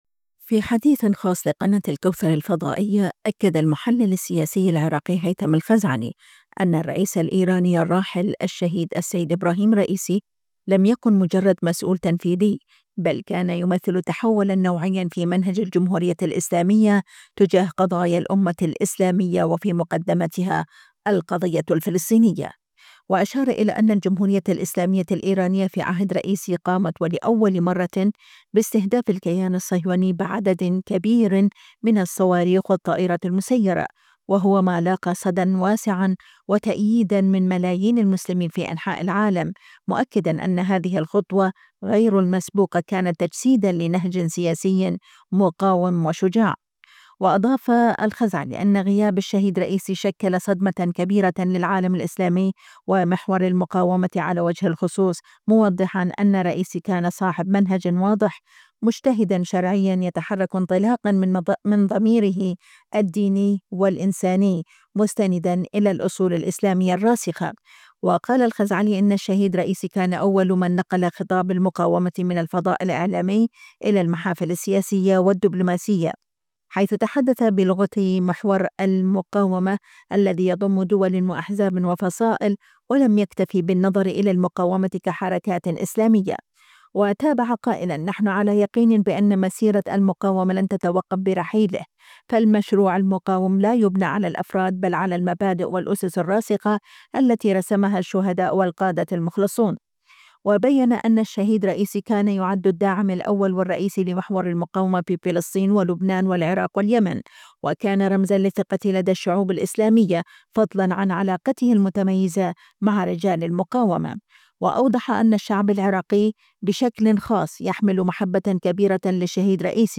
في مقابلة مع قناة الكوثر..
خاص الكوثر - مقابلات